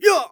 xys发力13.wav 0:00.00 0:00.33 xys发力13.wav WAV · 28 KB · 單聲道 (1ch) 下载文件 本站所有音效均采用 CC0 授权 ，可免费用于商业与个人项目，无需署名。
人声采集素材